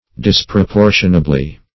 Meaning of disproportionably. disproportionably synonyms, pronunciation, spelling and more from Free Dictionary.
-- Dis`pro*por"tion*a*bly , adv.